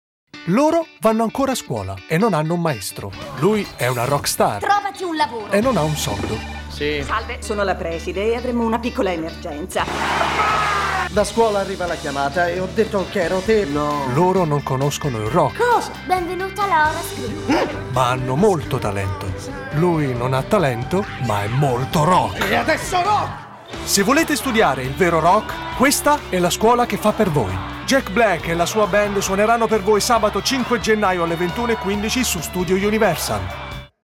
Speaker radio-televisivo. Voce calda e graffiata
Sprechprobe: eLearning (Muttersprache):